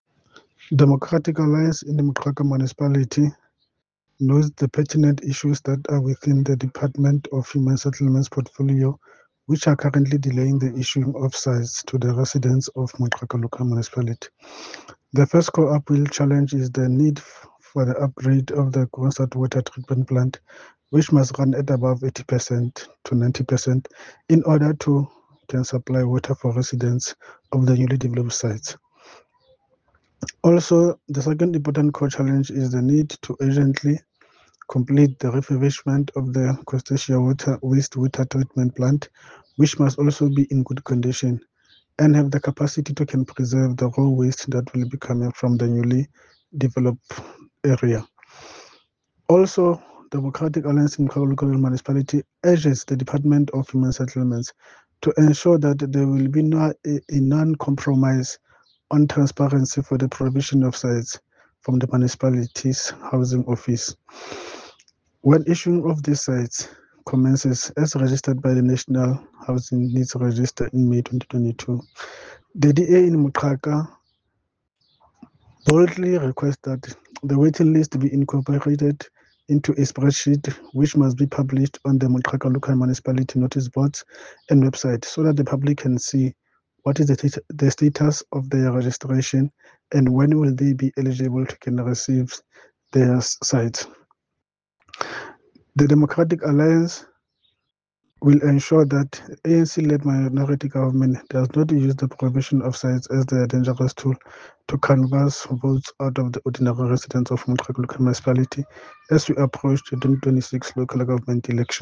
Sesotho soundbites by Cllr Sepatala Chabalala.